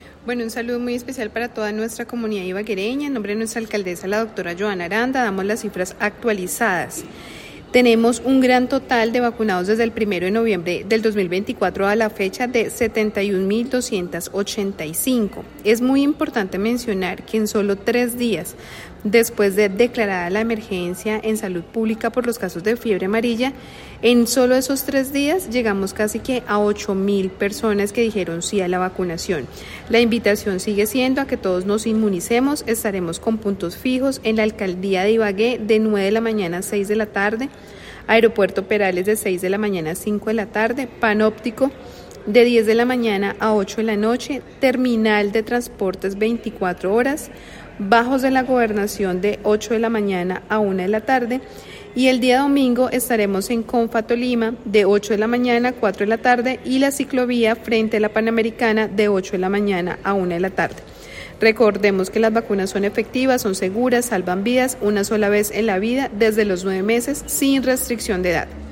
Secretaria de Salud Liliana Ospina entre el balance de vacunación fiebre amarilla en Ibagué:
Sec-Salud-Liliana-Ospina-balance-vacunacion-fiebre-amarilla.mp3